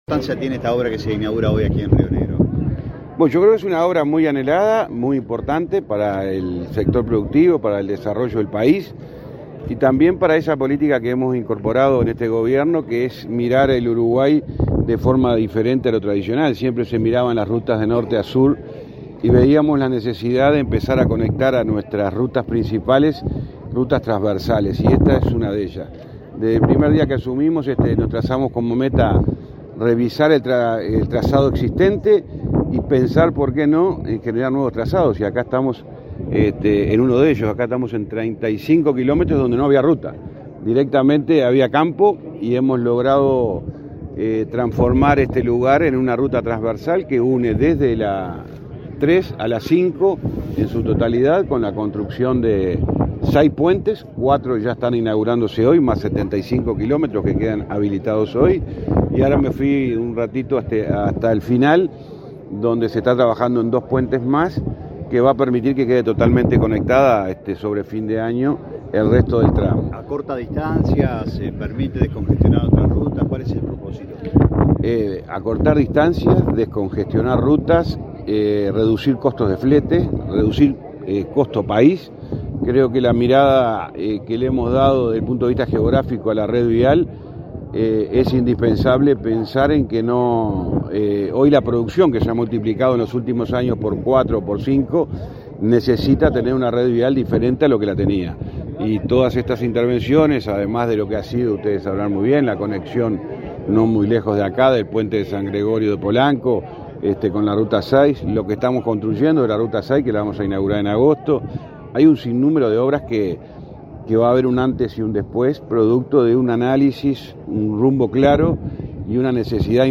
Declaraciones a la prensa del ministro de Transporte y Obras Públicas, José Luis Falero
Declaraciones a la prensa del ministro de Transporte y Obras Públicas, José Luis Falero 13/06/2024 Compartir Facebook X Copiar enlace WhatsApp LinkedIn Con la presencia del presidente de la República, Luis Lacalle Pou, fueron inauguradas, este 13 de junio, las obras sobre ruta 20. En la oportunidad, el ministro de Transporte y Obras Públicas, José Luis Falero, realizó declaraciones a la prensa.